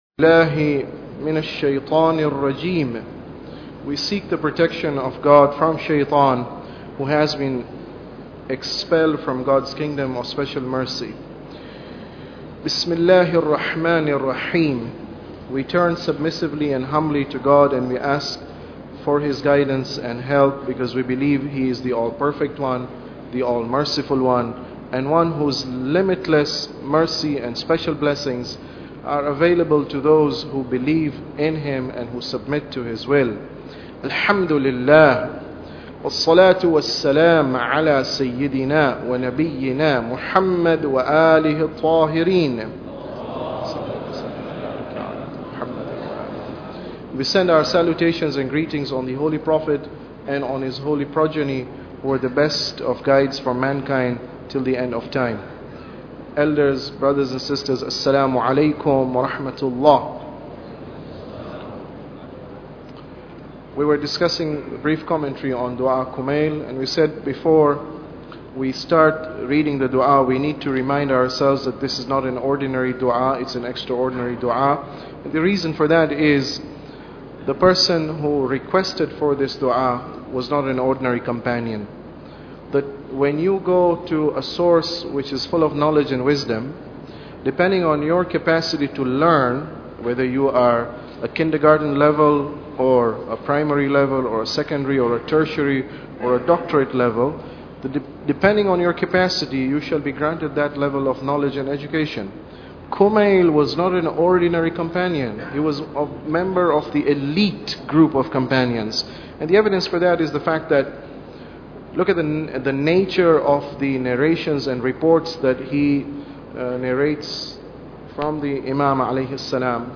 Tafsir Dua Kumail Lecture 6